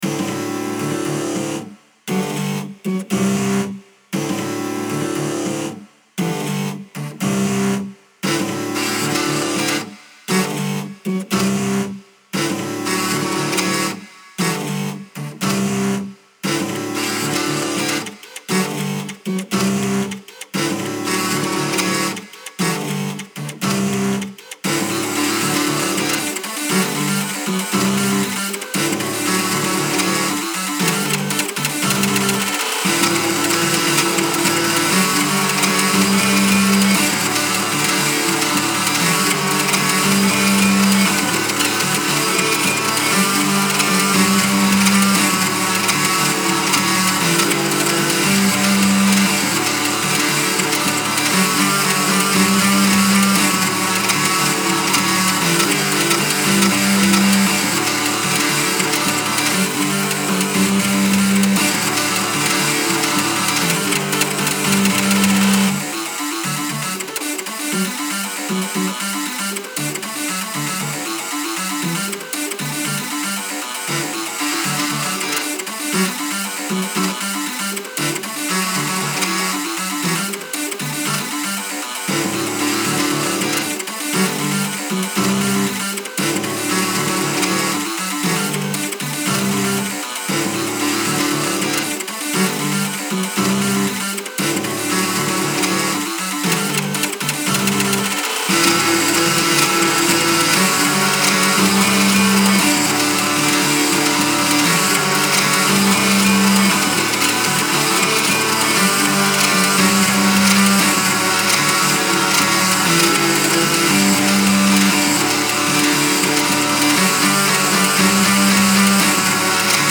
Style Style EDM/Electronic, Other
Mood Mood Bright
Featured Featured Synth
BPM BPM 117